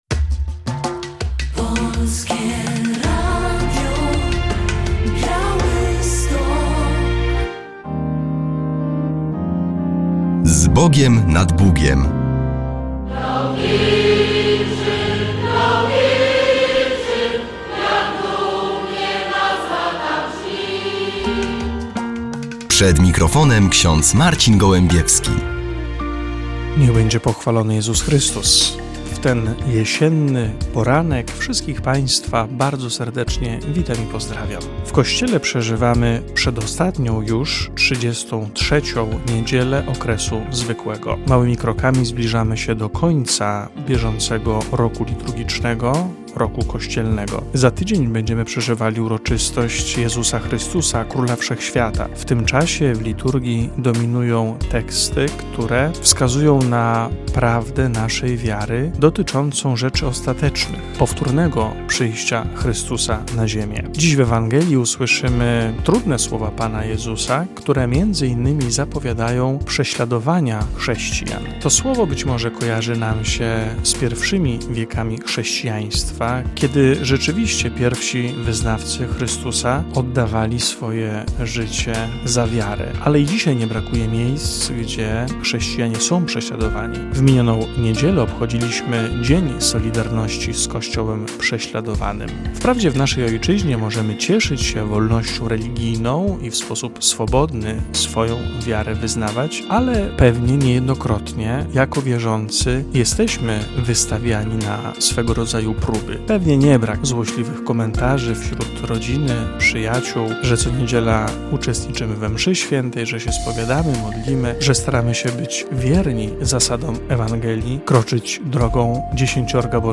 Relacja z obchodów Święta Niepodległości